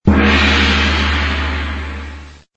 Gong Sound Sound Effect Free Download
Gong Sound